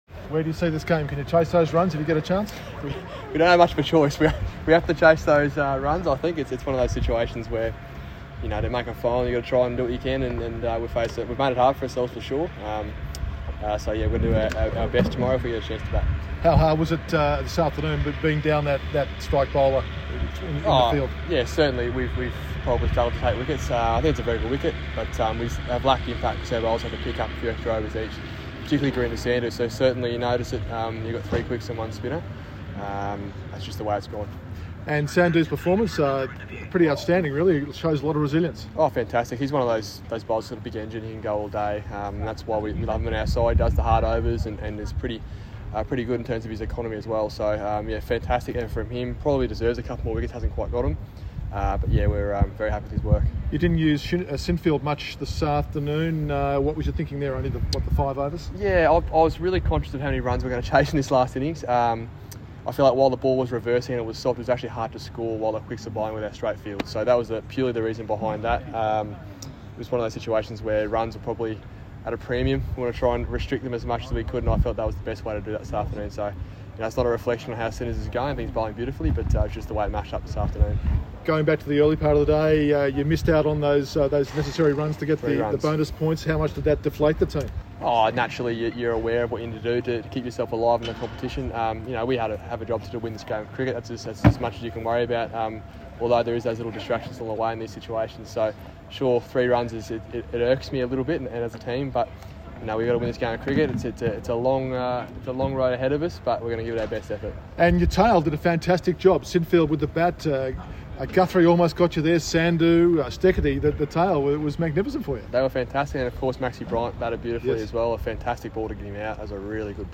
QLD captain Jimmy Pierson speaking at stumps in Hobart today.